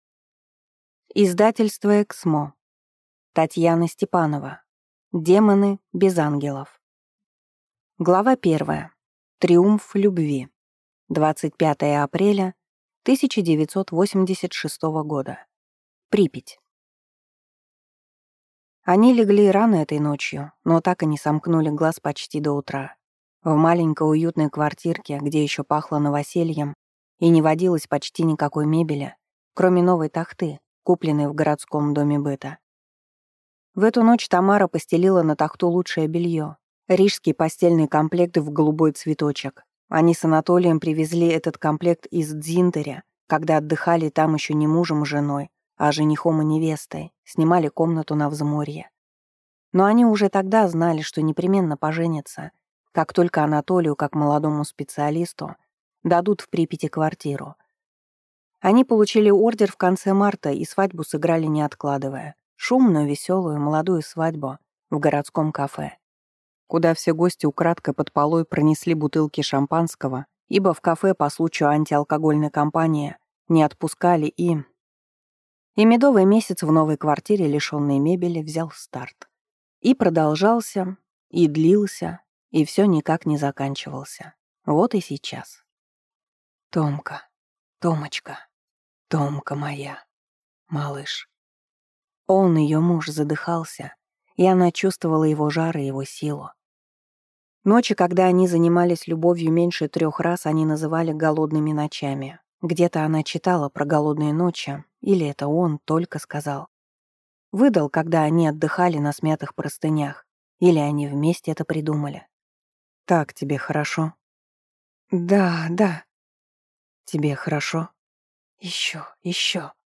Аудиокнига Демоны без ангелов | Библиотека аудиокниг
Прослушать и бесплатно скачать фрагмент аудиокниги